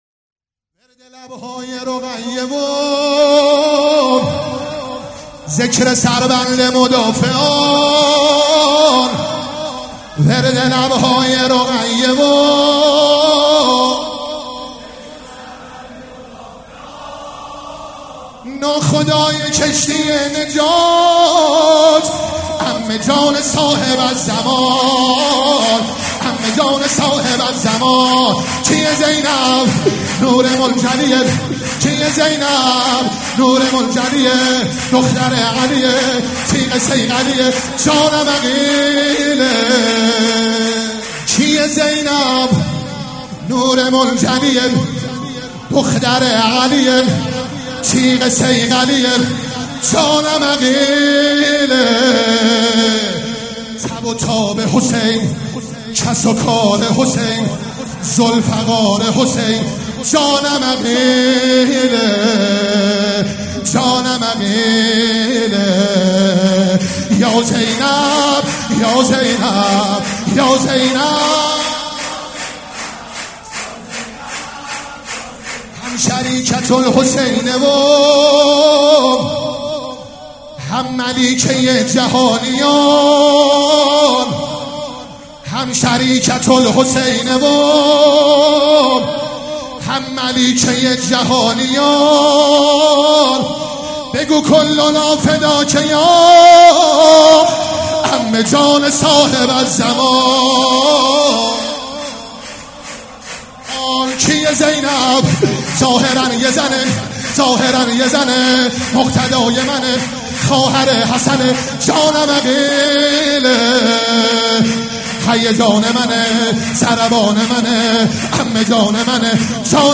هیئت ریحانه الحسین سلام الله علیها
سبک اثــر شور مداح